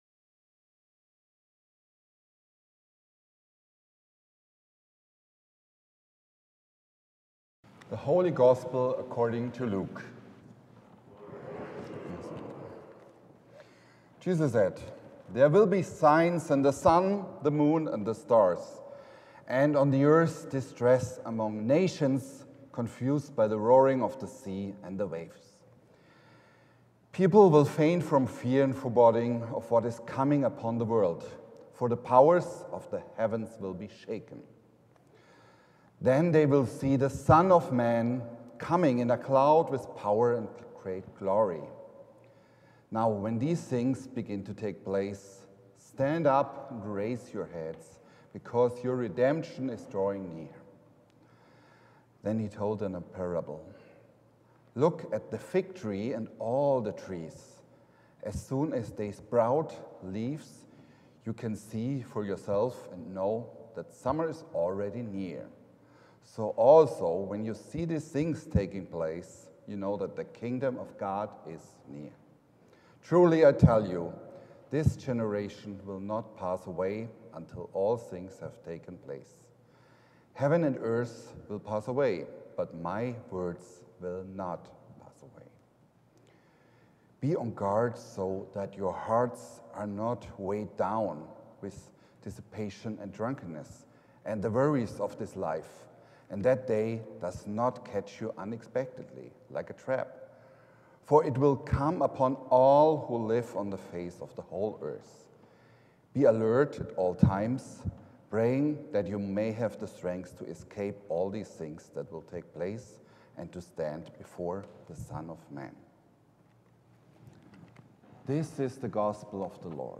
12.1.24-Sermon_EDIT.mp3